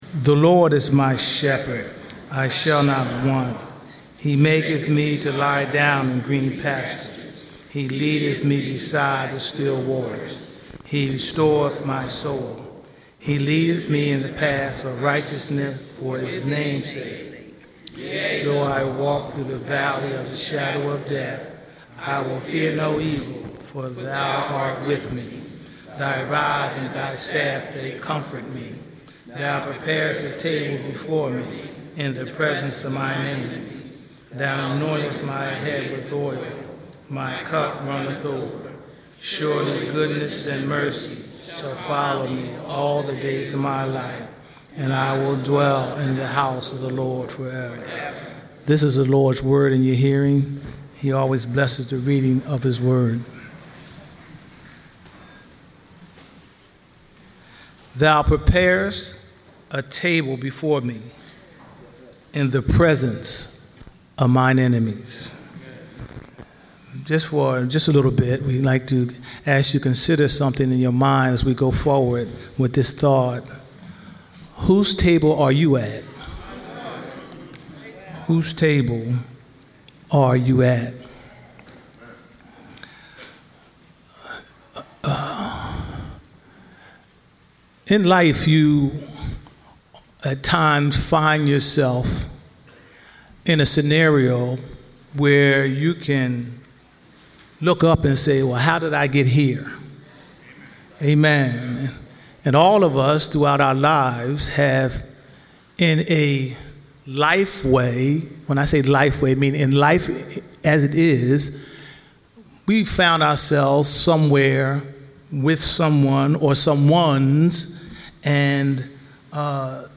Sermon Archive Home > Sermon Archive > Worship > View Sermon Whose Table Are You At?